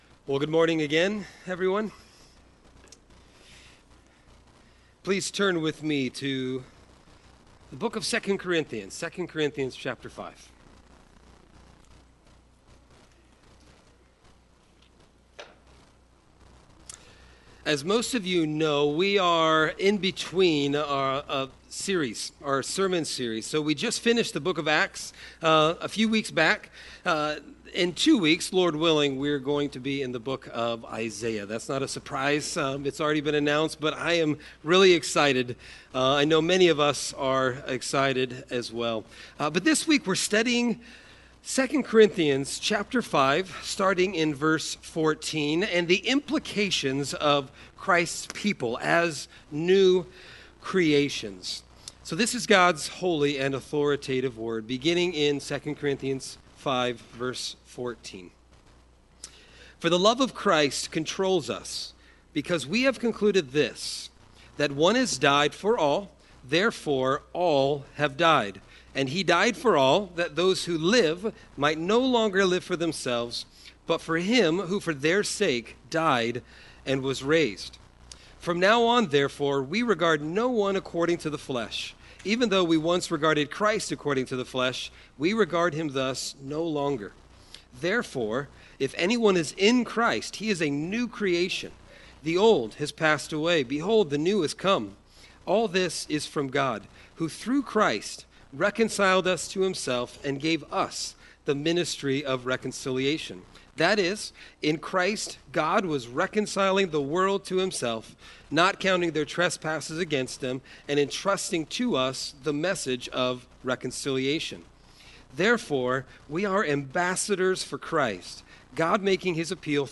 Sermon Text: 2 Corinthians 5:14-6:2